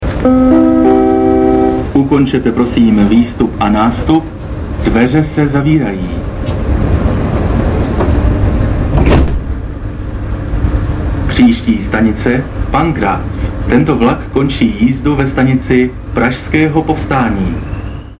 - Vlakový rozhlas: